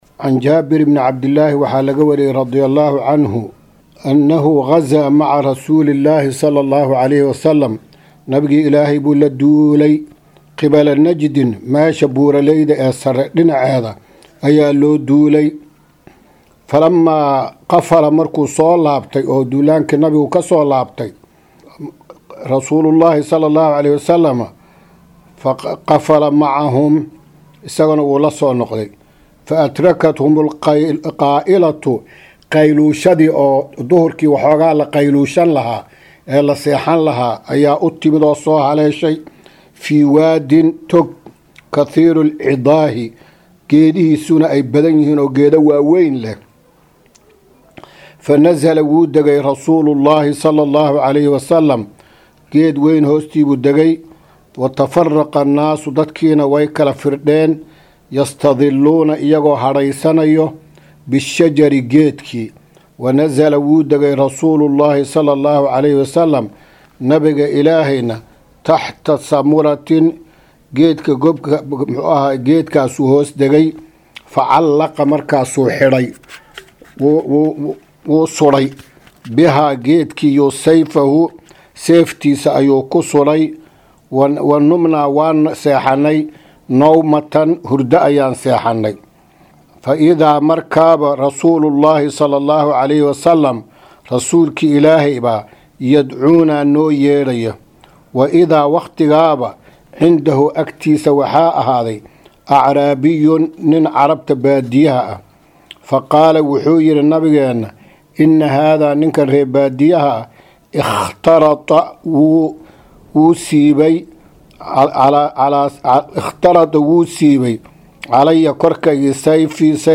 Maqal- Riyaadu Saalixiin – Casharka 10aad